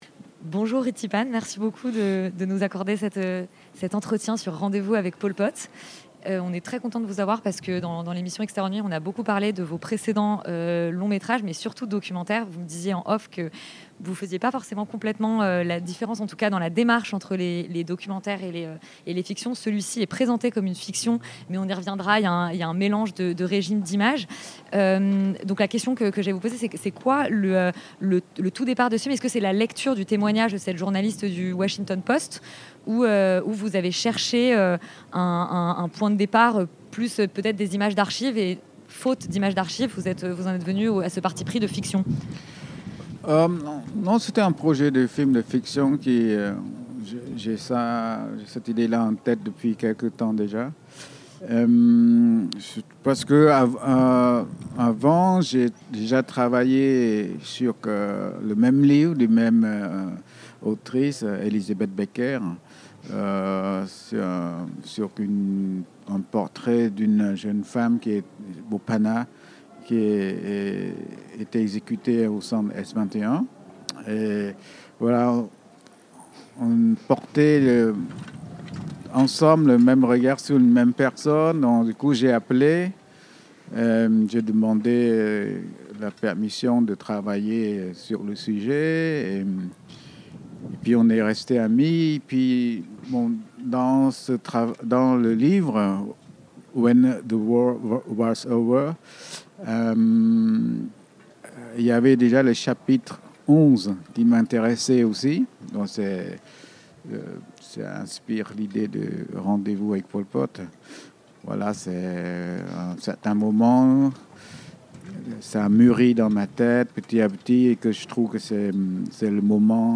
Extérieur Nuit x Cannes 2024 - Interview de RITHY...